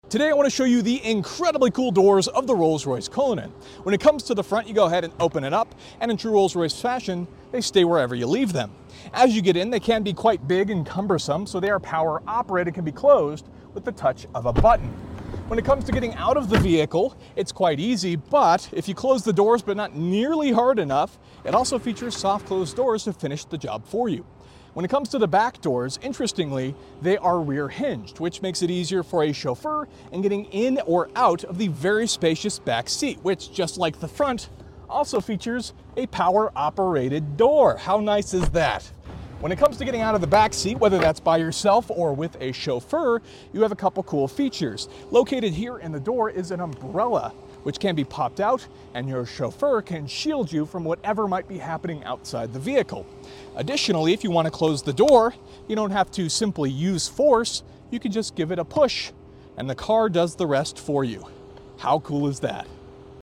Cools Doors of the Rolls sound effects free download
Cools Doors of the Rolls Royce Cullinan